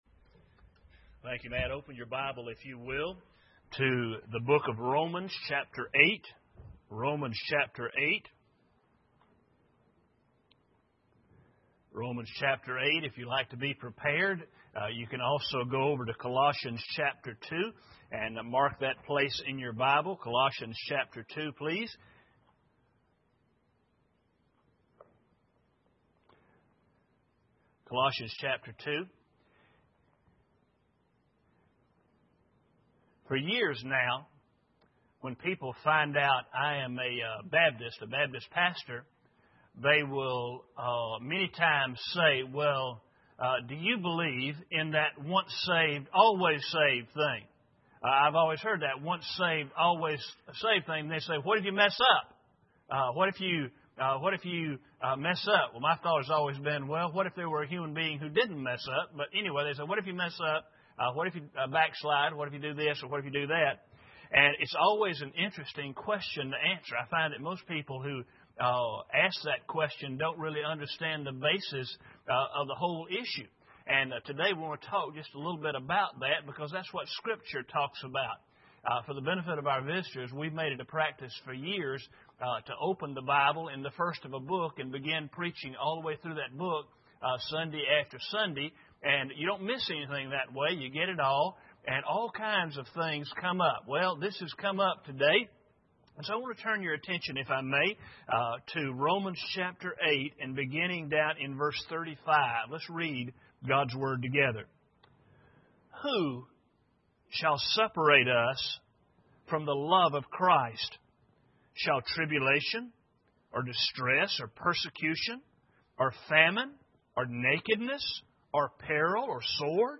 Romans 8:35-37 Service Type: Sunday Morning Bible Text